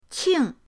qing4.mp3